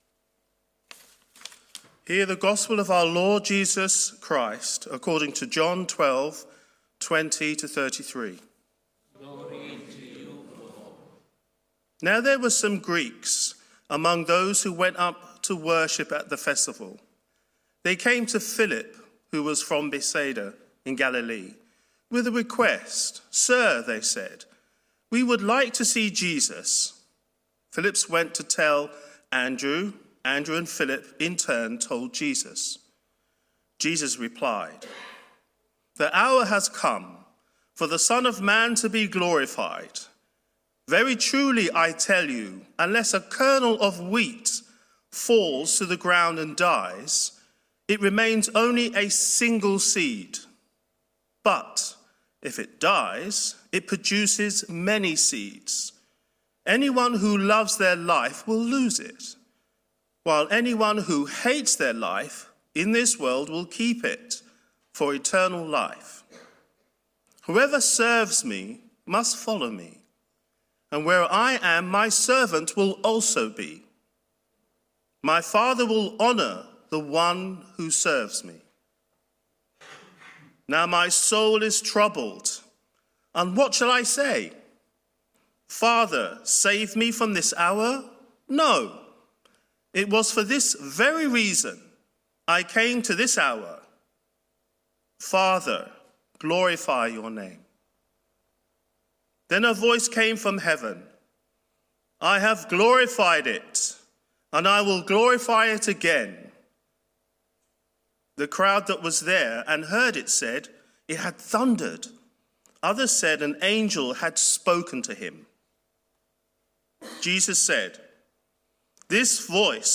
Audio Sermon-March 17, 2024